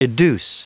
Prononciation du mot educe en anglais (fichier audio)
Prononciation du mot : educe